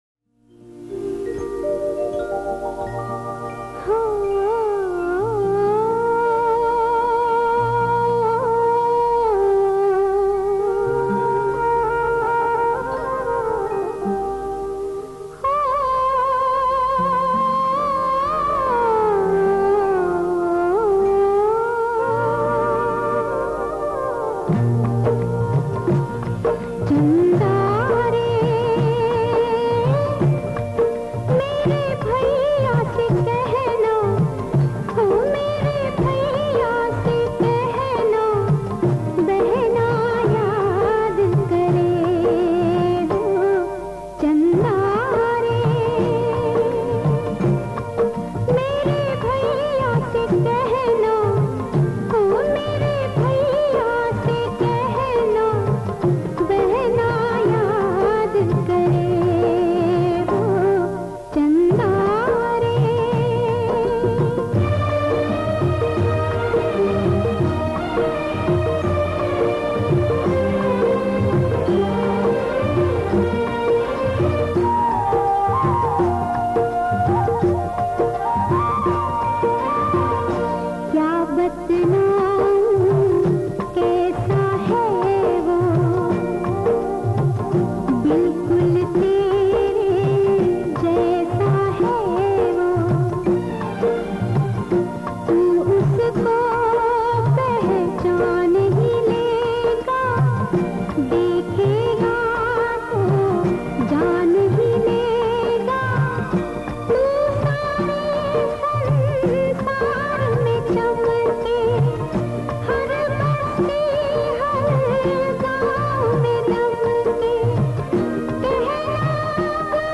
soulful voice